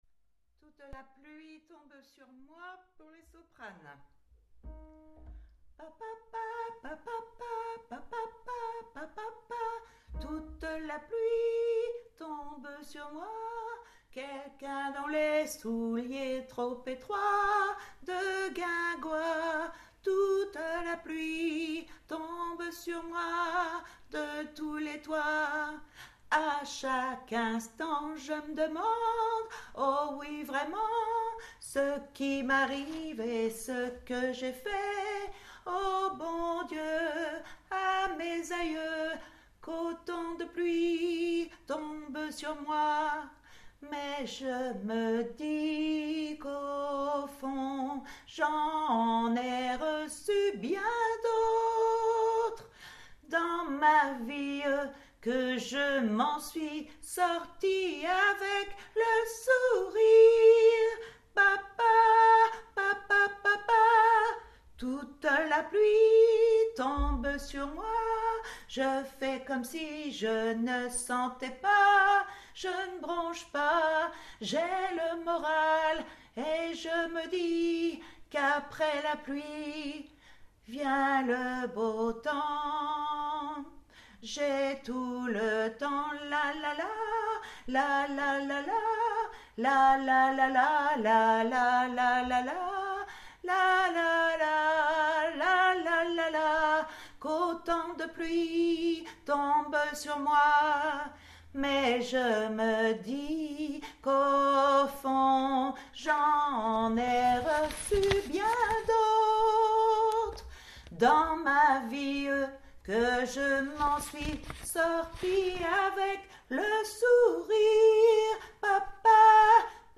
Toute la pluie Sop